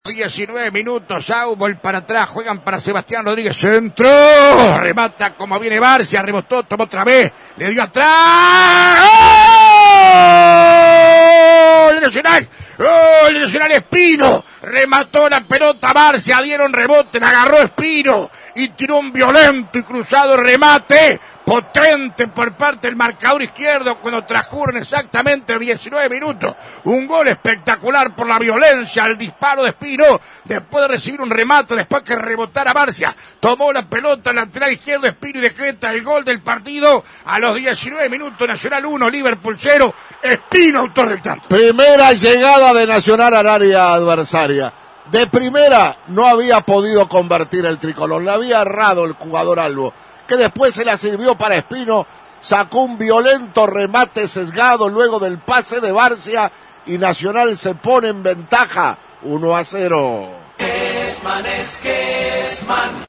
En un primer tiempo parejo y algo deslucido, Nacional alcanzó la ventaja mediante conquista de Espino que así relató Alberto Kesman: